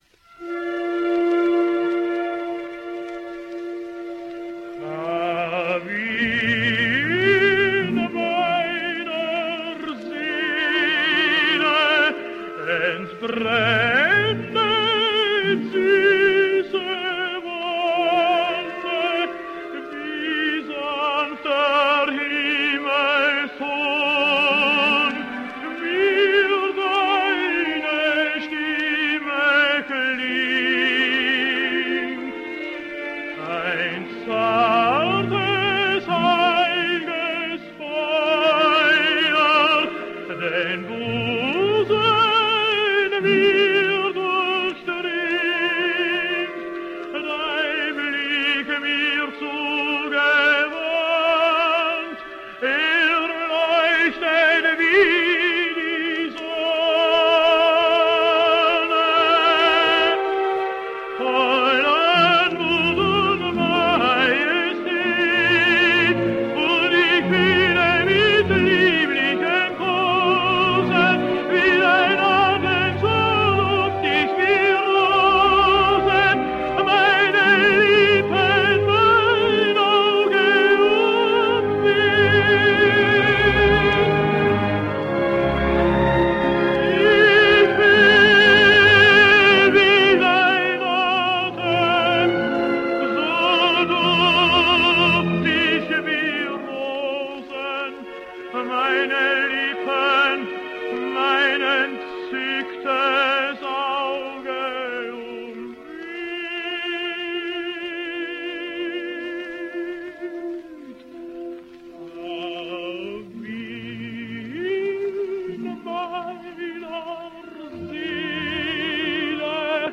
Austrian tenor.
Both Patzak and Erb were noted for the silvery timbre of their voices.